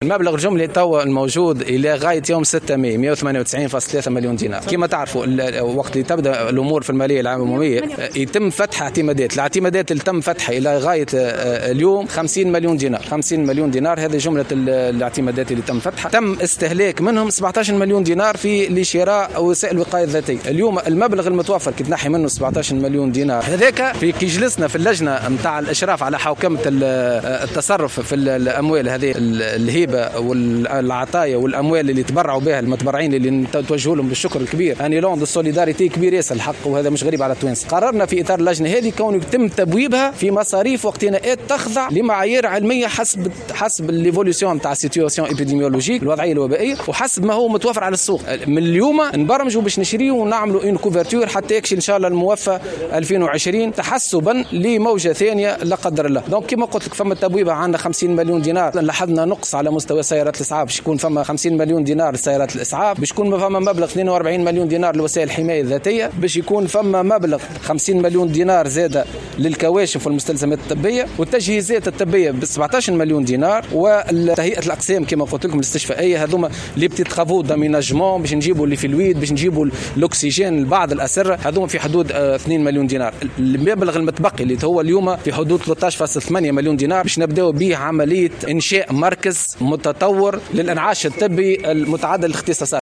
إثر ندوة صحفية عُقدت بوزارة الصحة